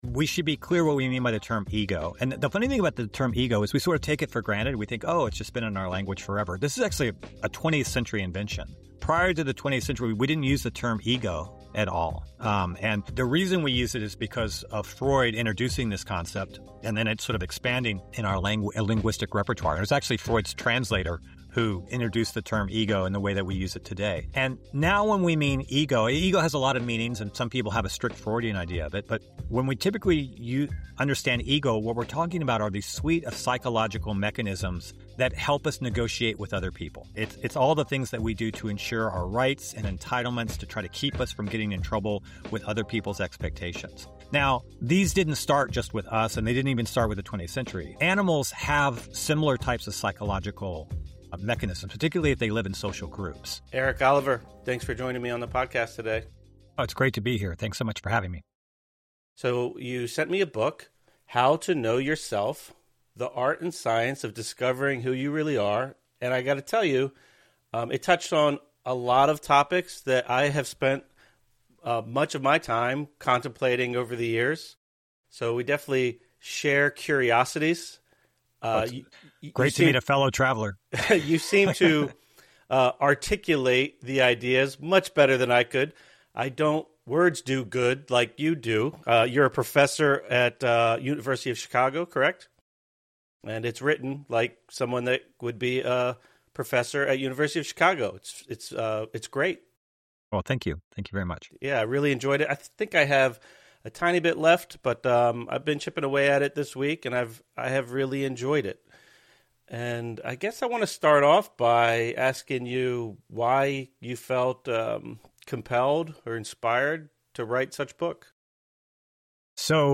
We also have interviews with a broad range of folks to help empower you to play a larger role in your sense of well-being.